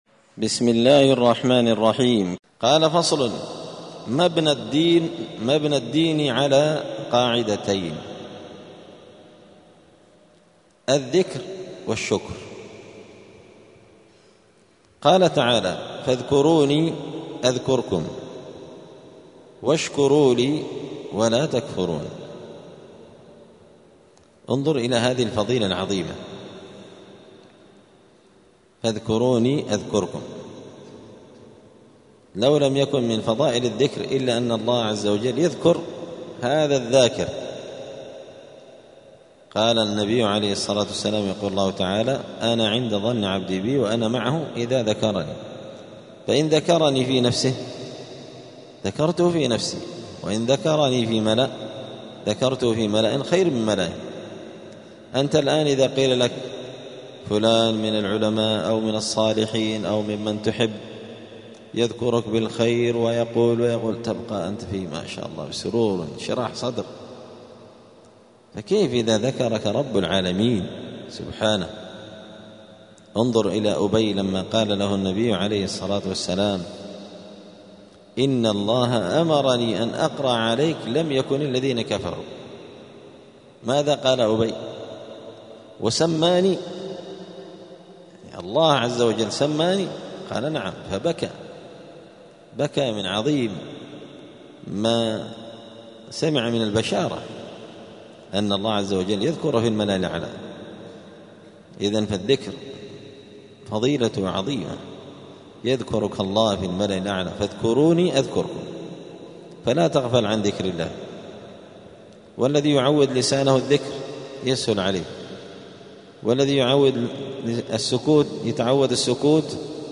*الدرس التاسع والستون (69) {فصل: مبنى الدين على قاعدتين الذكر والشكر}*